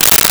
Camera Manual Click 02
Camera Manual Click 02.wav